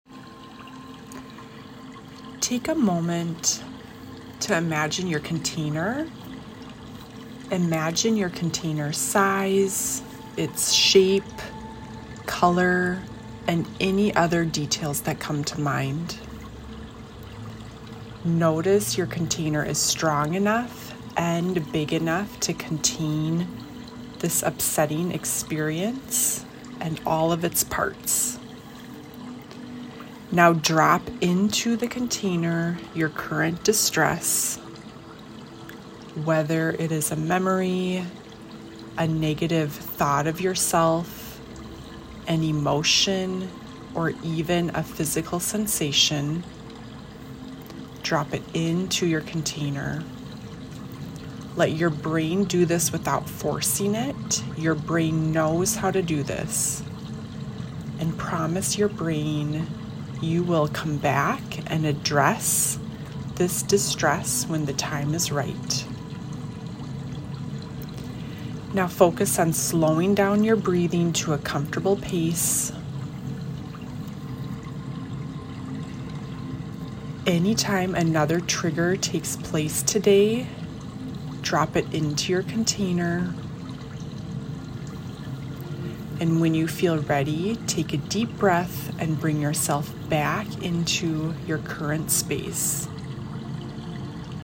A guided meditation for containment when things feel big, intense, or overwhelming.